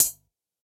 UHH_ElectroHatD_Hit-23.wav